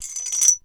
34. 34. Percussive FX 33 ZG